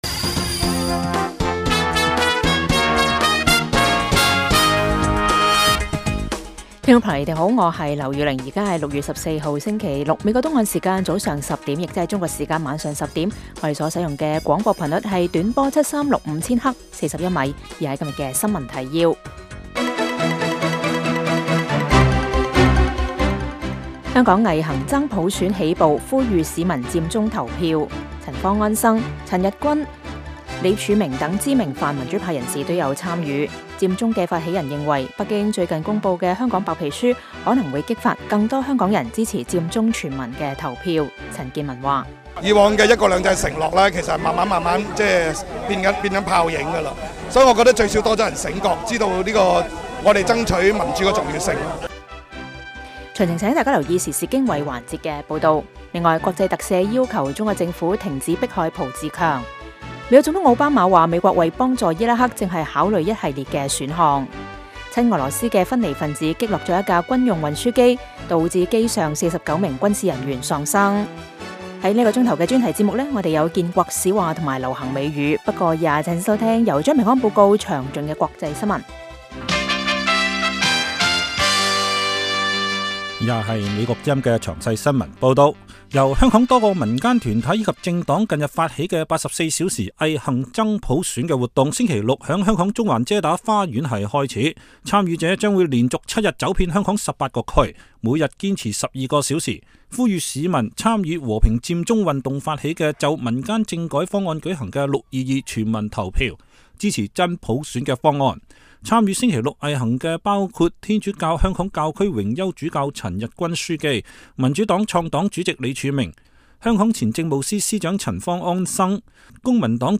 每晚 10點至11點 (1300-1400 UTC)粵語廣播，內容包括簡要新聞、記者報導和簡短專題。